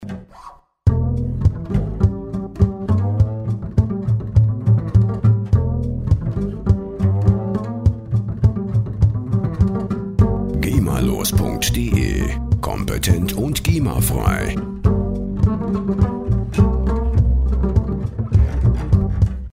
Instrument: Kontrabass
Tempo: 103 bpm